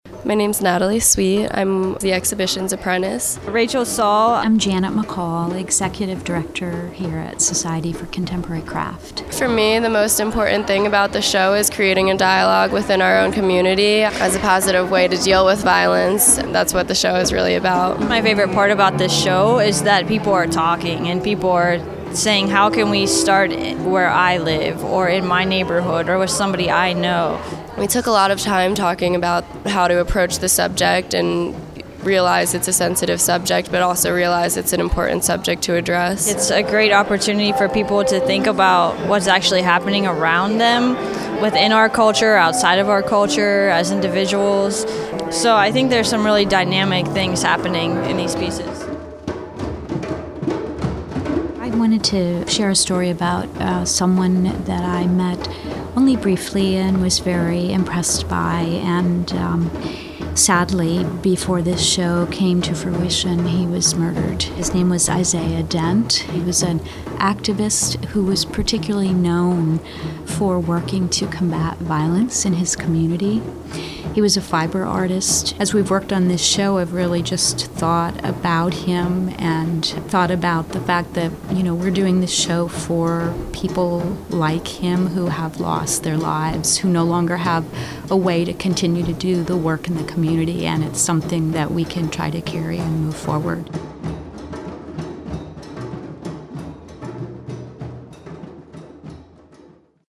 ENOUGH Violence: Artists Speak Out opening reception
Hear visitors, artists and Contemporary Craft staff react to the art and the exhibition below.